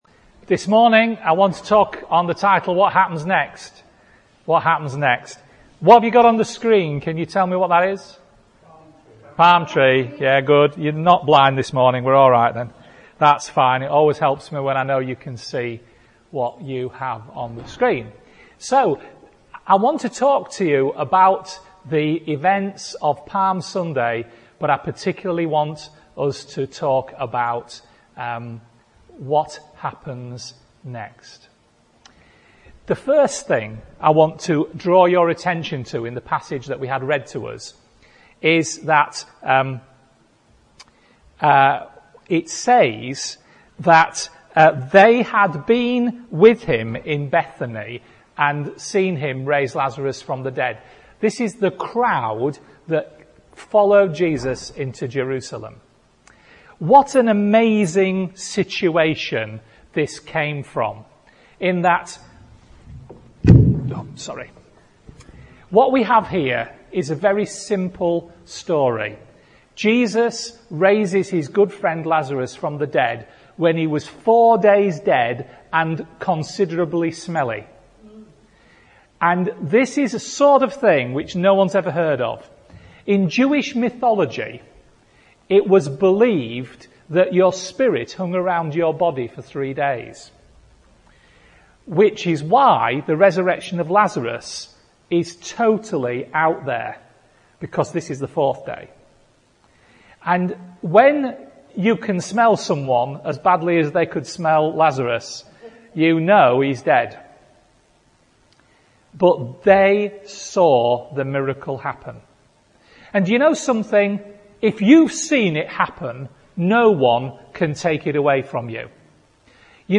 A palm Sunday message